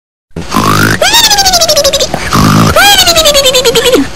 Goofy Ahh Snore
Goofy Ahh Snore Sound Effect for Memes
goofy-ahh-snore.mp3